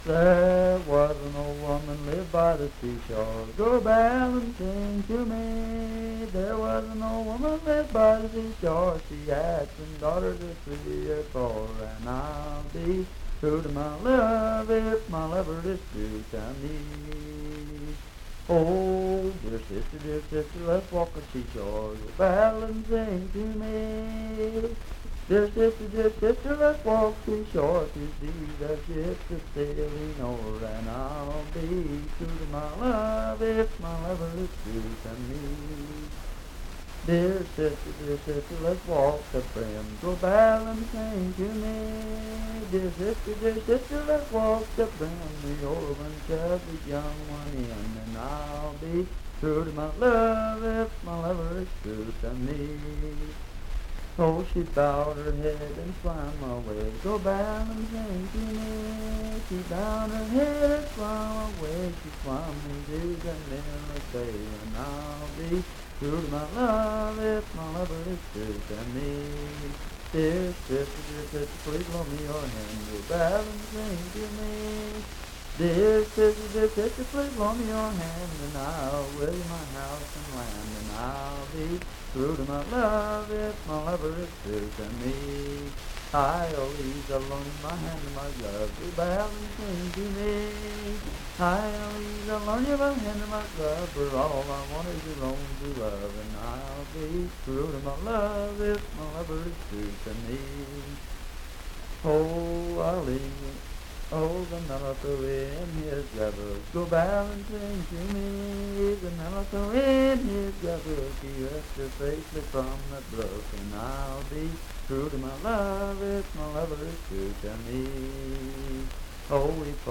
Unaccompanied vocal music
Verse-refrain 10(8w/R).
Voice (sung)
Franklin (Pendleton County, W. Va.), Pendleton County (W. Va.)